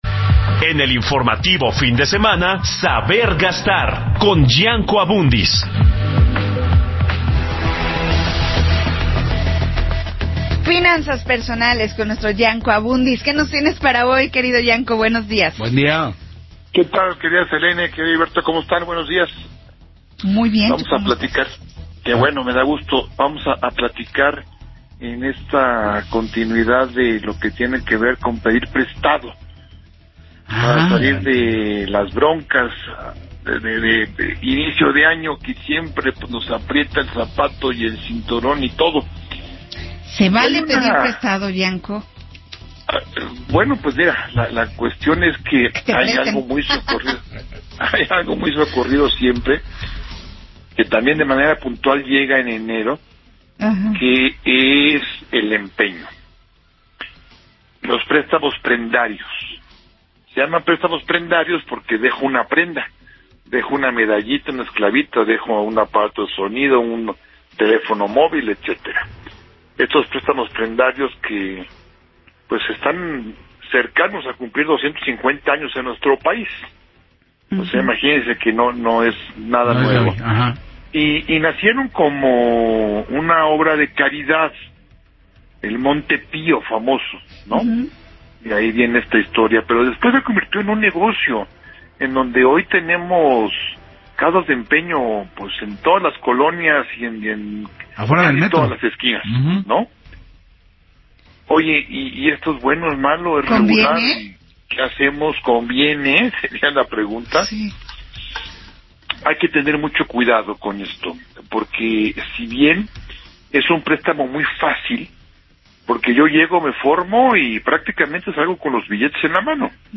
se presenta también una entrevista con el especialista en finanzas personales